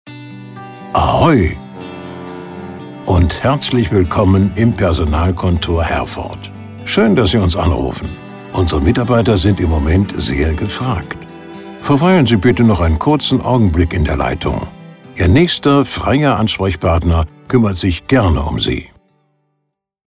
Telefonansage Personalkontor
Personalkontor Herford – Begrüßungsansage